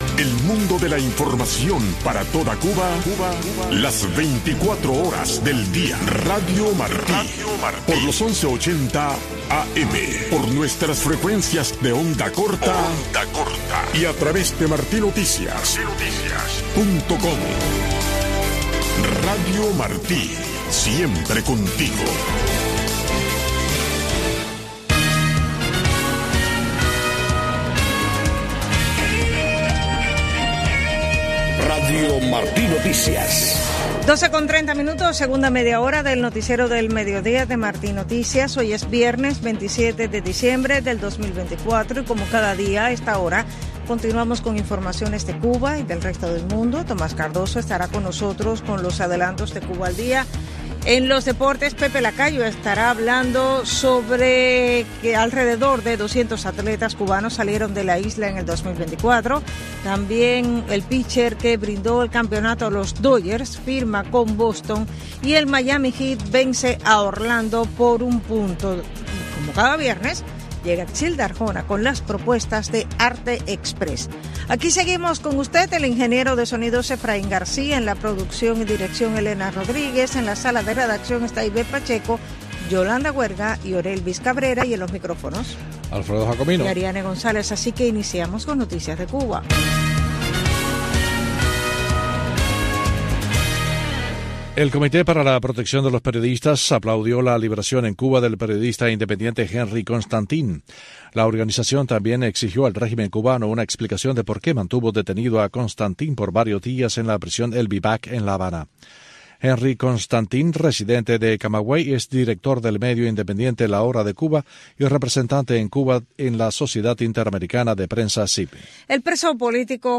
Noticiero de Radio Martí 12:00 PM | Segunda media hora